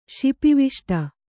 ♪ śipiviṣṭa